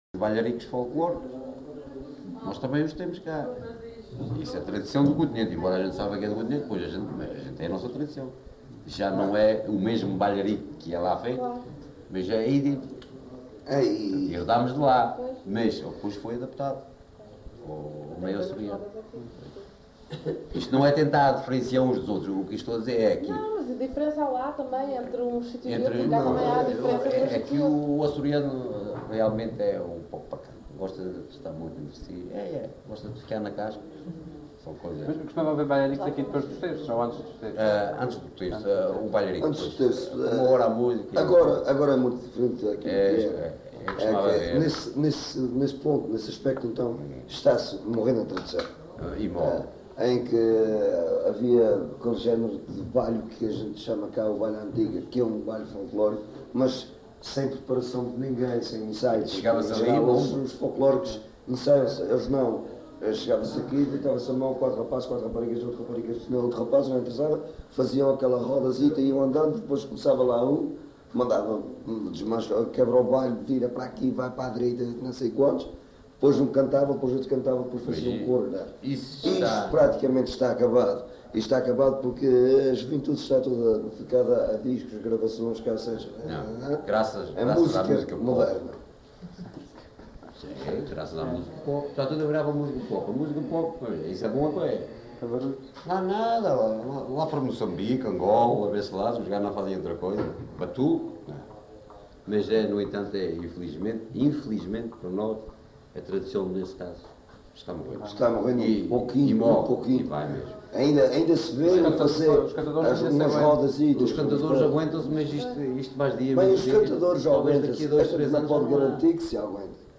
LocalidadeFontinhas (Praia da Vitória, Angra do Heroísmo)